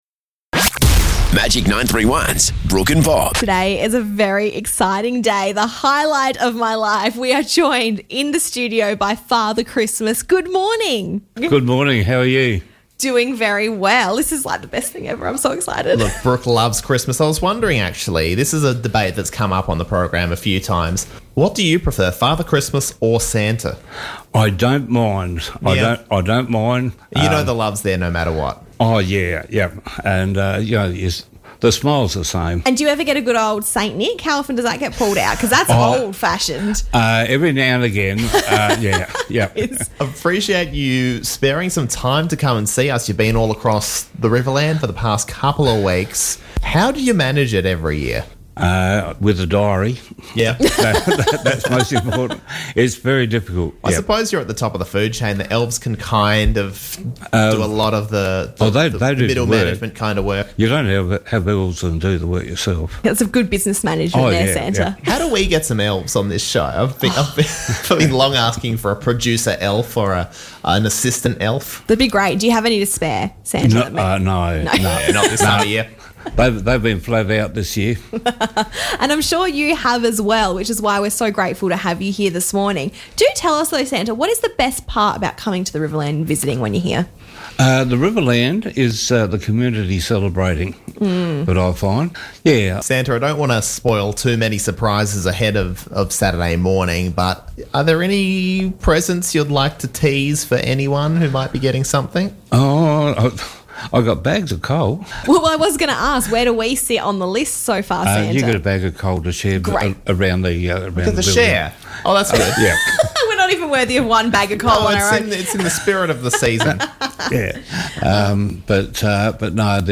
Santa in the studio!